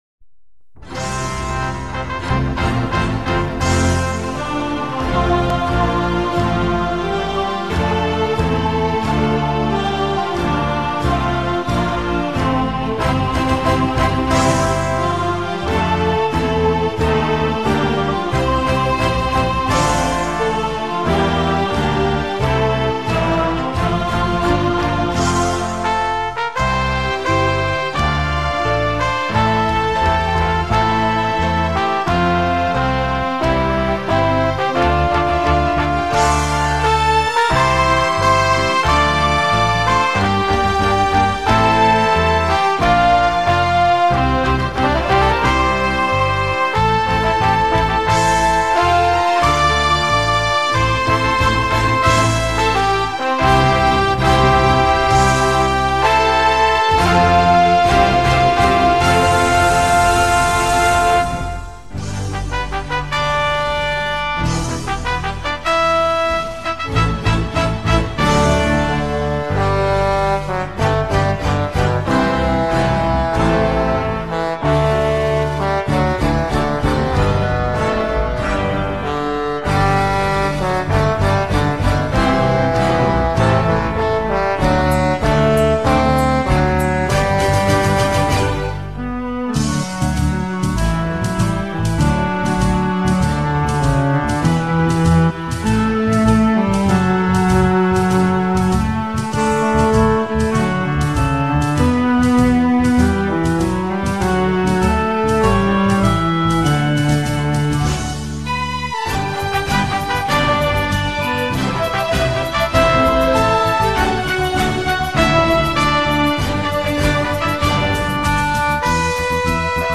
quoc-ca-quoc-te-ca-beat.mp3